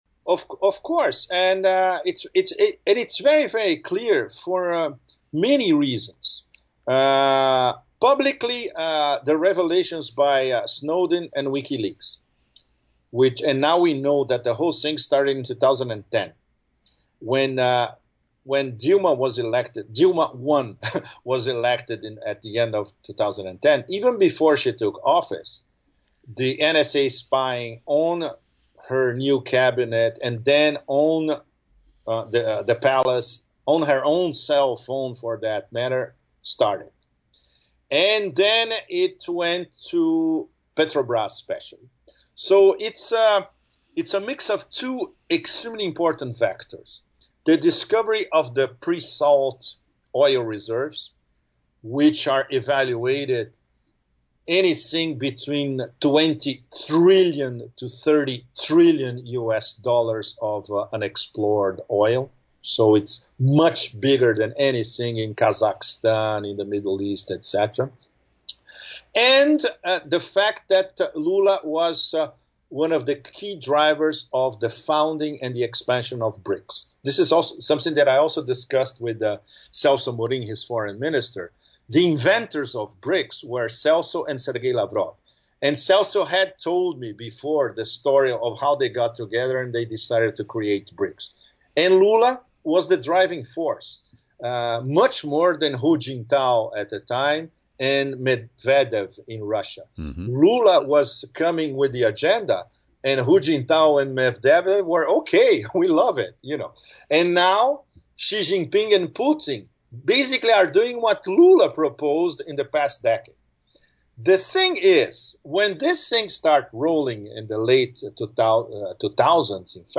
We open this interview with Escobar’s observations on the recent attack on the Saudi oil complex at Abqaiq.